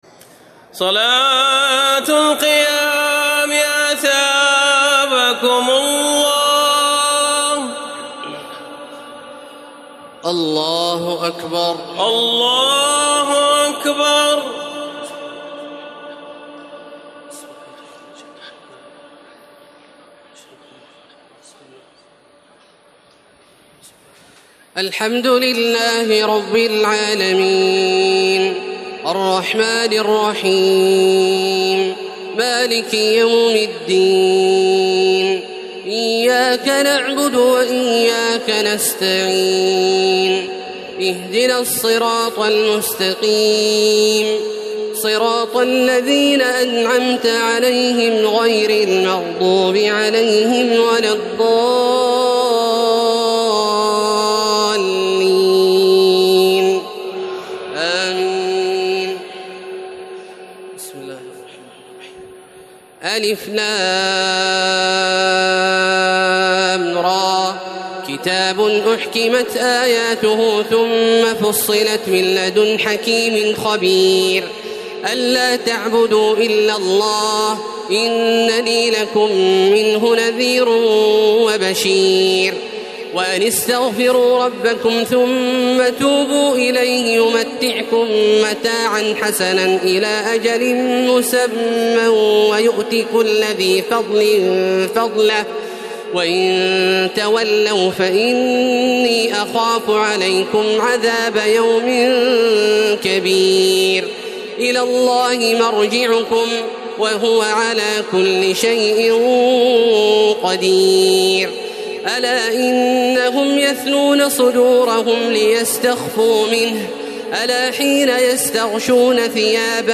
تراويح الليلة الحادية عشر رمضان 1432هـ من سورة هود (1-83) Taraweeh 11 st night Ramadan 1432H from Surah Hud > تراويح الحرم المكي عام 1432 🕋 > التراويح - تلاوات الحرمين